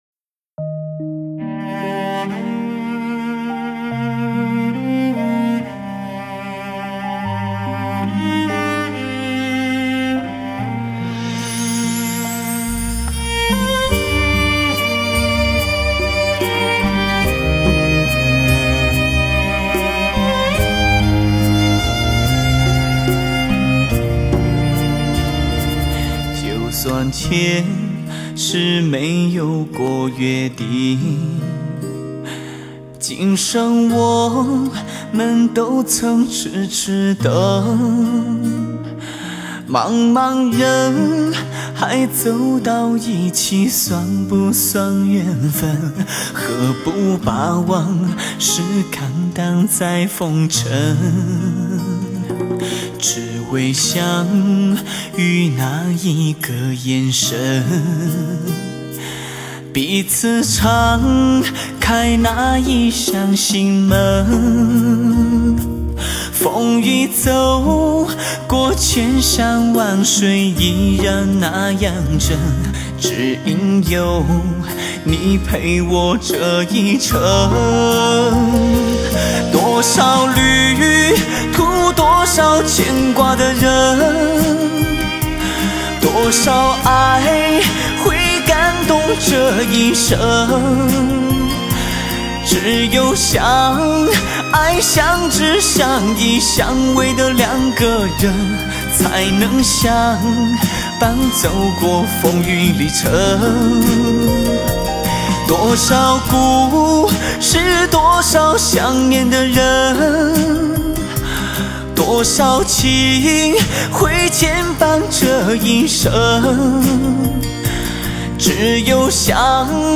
忧郁的歌声 蓝色的心情
笛子、箫
二胡
古筝
琵琶
吉它
小提琴
大提琴
SAX